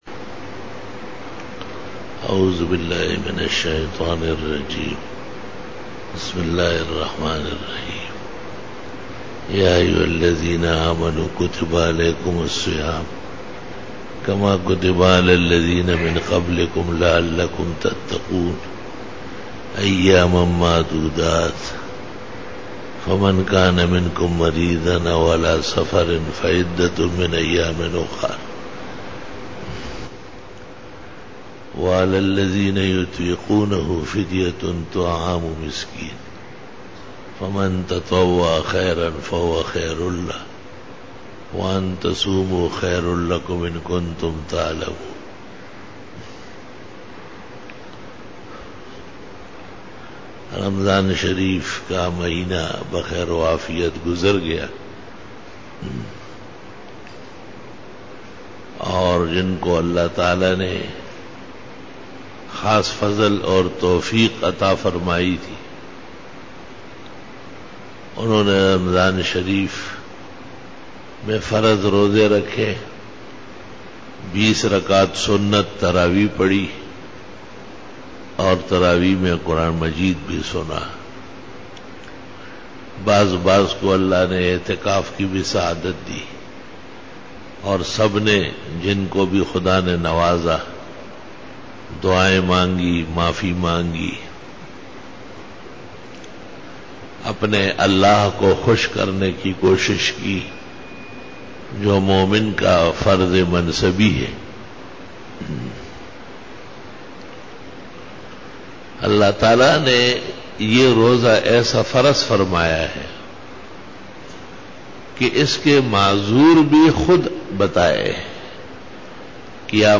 31_Bayan e Juma tul Mubarak 9-August-2013